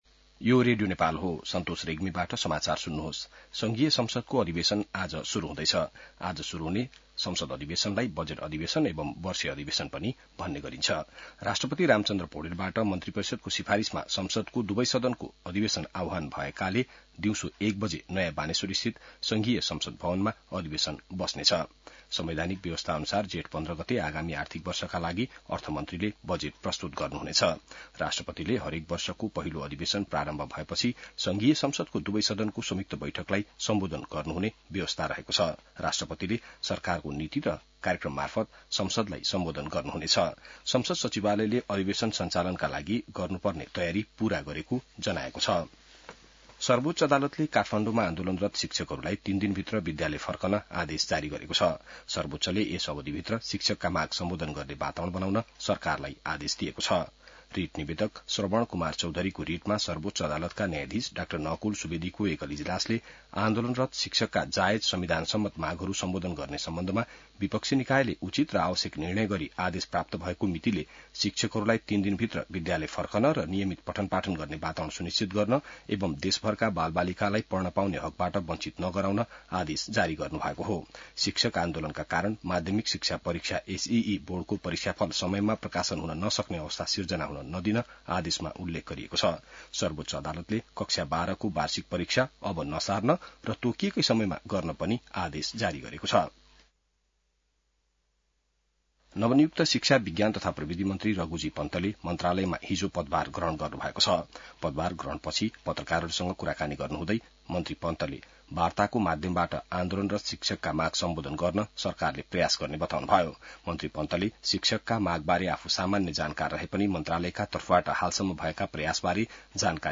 बिहान ६ बजेको नेपाली समाचार : १२ वैशाख , २०८२